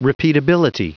Prononciation du mot repeatability en anglais (fichier audio)
Prononciation du mot : repeatability
repeatability.wav